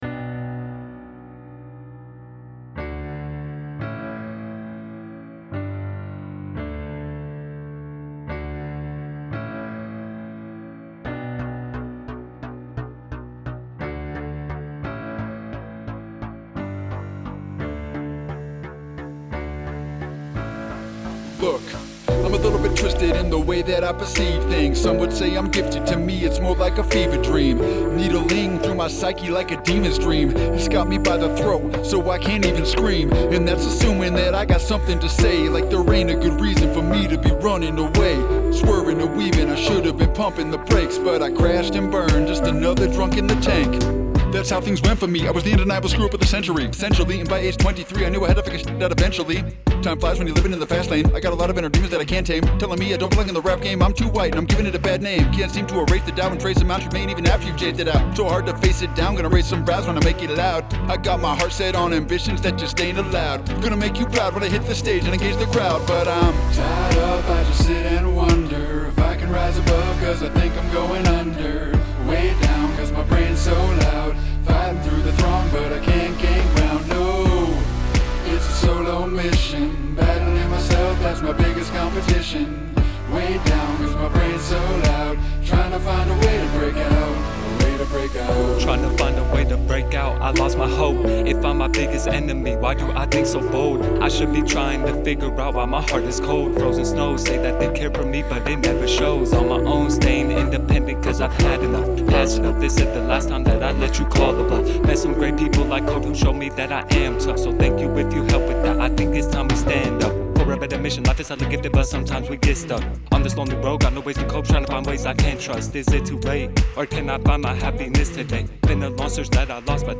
Clean Radio edit